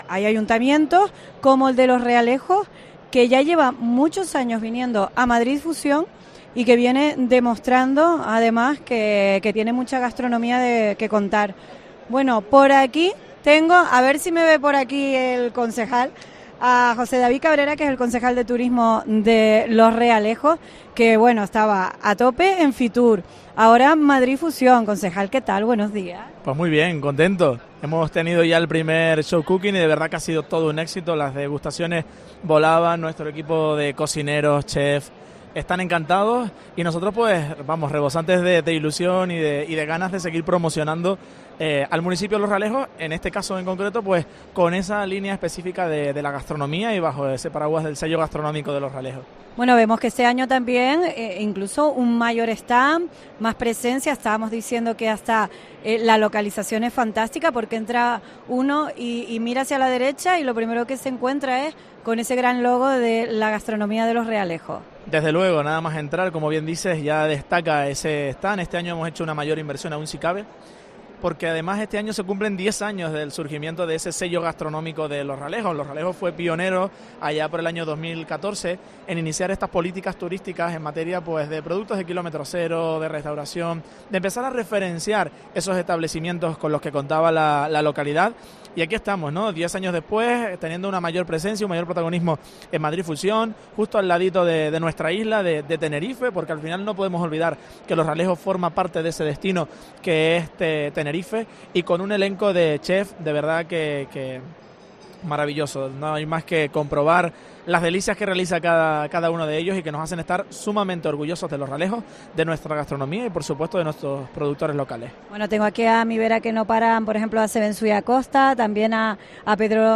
Entrevista David Cabrera, concejal turismo de Los Realejos en Madrid Fusión